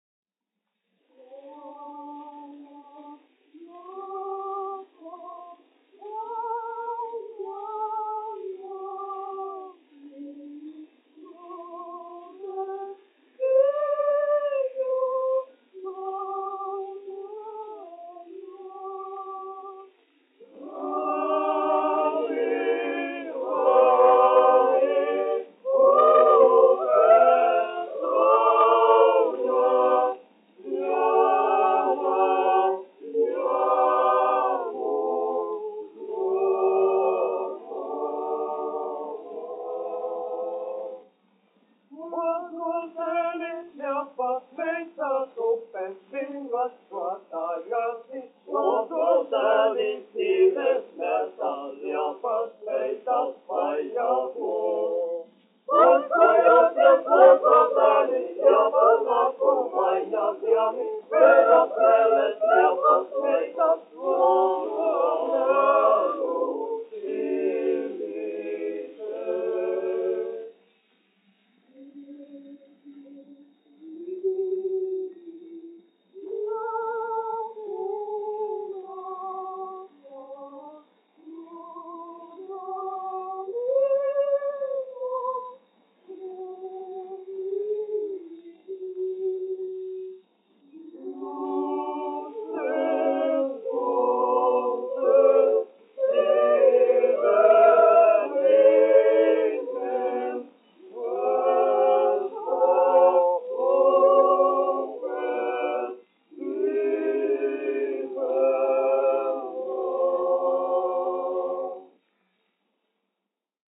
1 skpl. : analogs, 78 apgr/min, mono ; 25 cm
Kori (jauktie)
Skaņuplate
Latvijas vēsturiskie šellaka skaņuplašu ieraksti (Kolekcija)